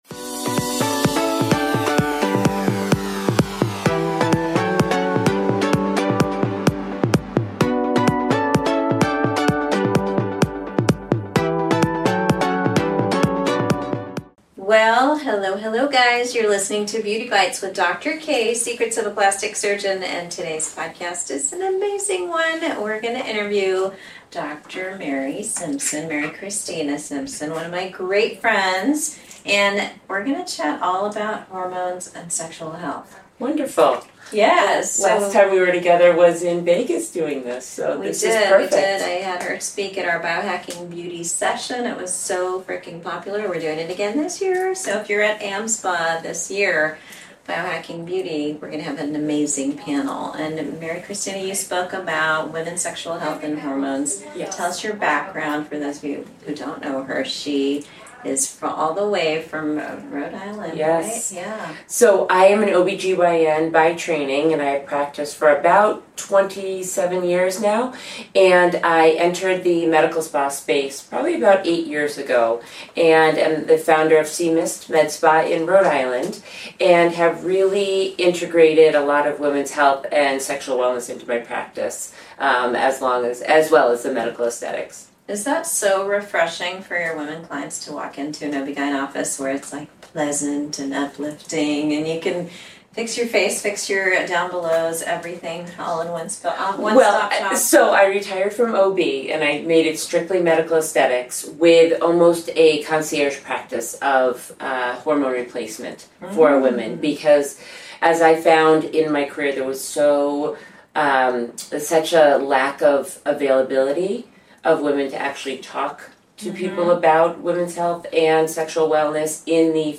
We talk openly about changes that come with aging as a woman, perimenopause, and why owning your sexual wellness is an act of self-care. This conversation is honest, science-based, and made to remind you that you’re not alone- and you deserve to feel your best at every stage of life.